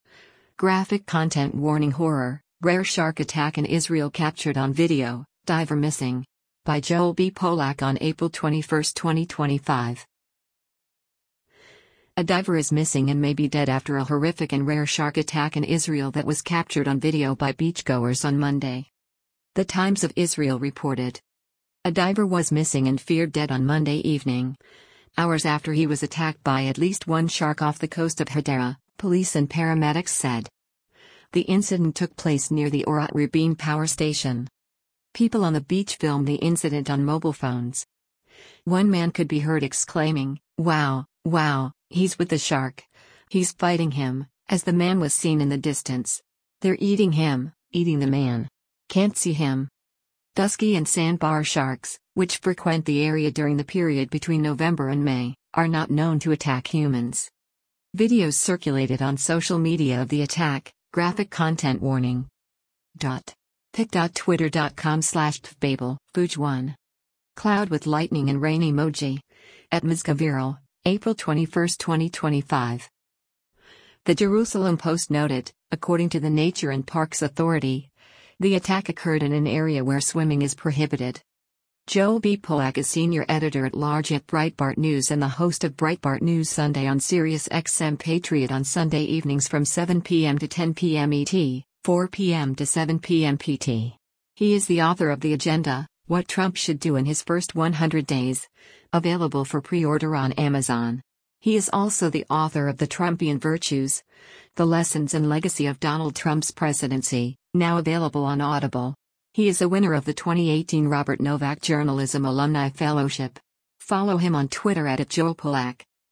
People on the beach filmed the incident on mobile phones. One man could be heard exclaiming, “Wow, wow, he’s with the shark, he’s fighting him,” as the man was seen in the distance.